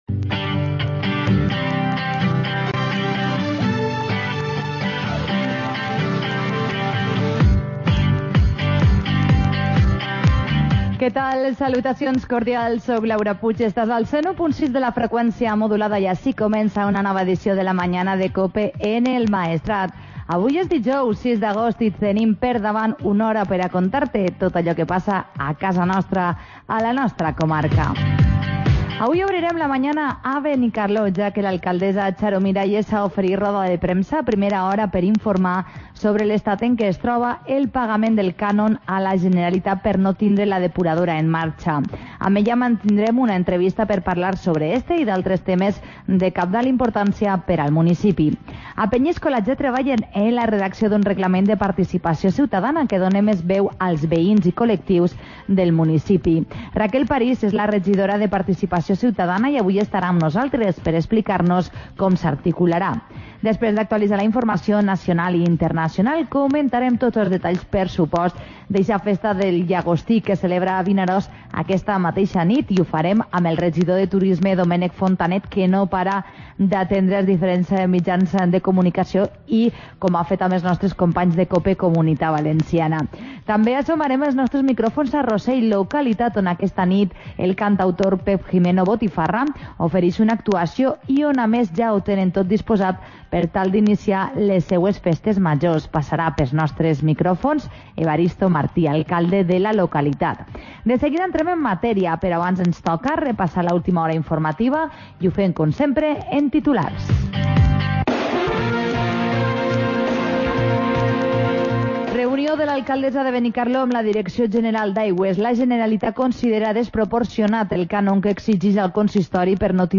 Magazine del Maestrat